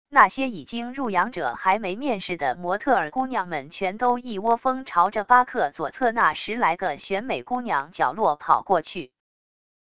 那些已经入羊者还没面试的模特儿姑娘们全都一窝蜂朝着巴克左侧那十来个选美姑娘角落跑过去wav音频生成系统WAV Audio Player